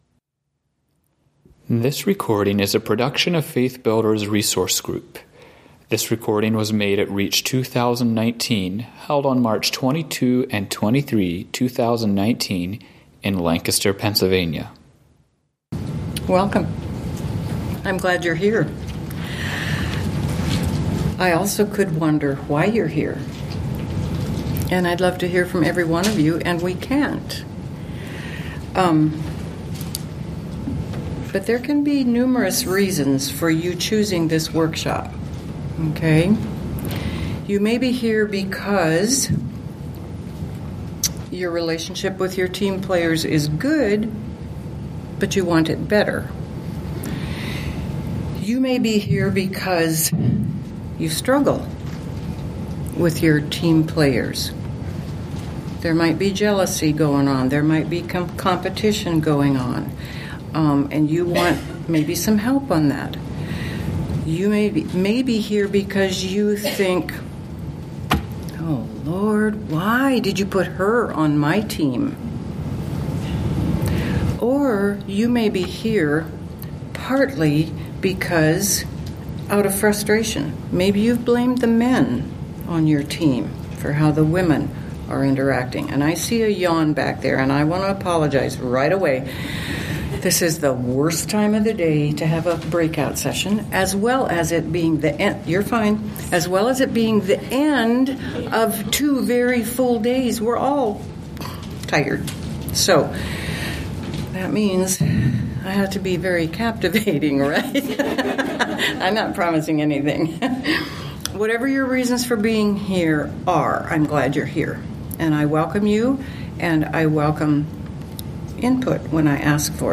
Home » Lectures » Cultivating Healthy Relationships Between Ministry Team Wives